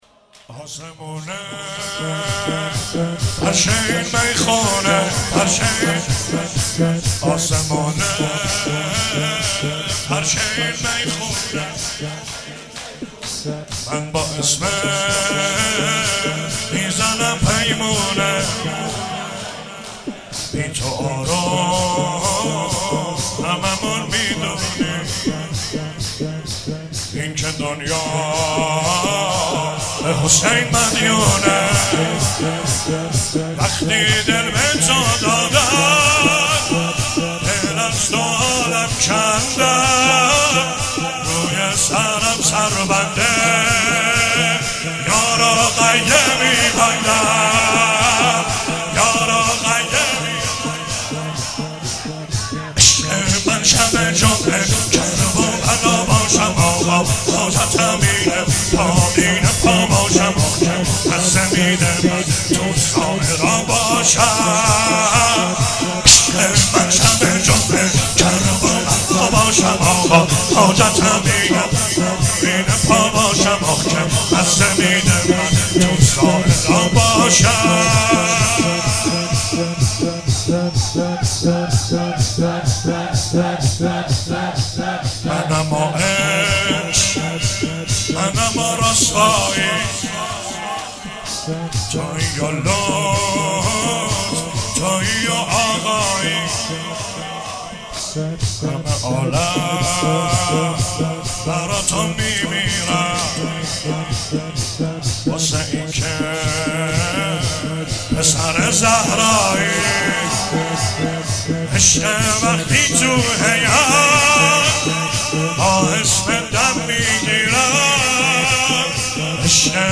چهاراه شهید شیرودی حسینیه حضرت زینب (سلام الله علیها)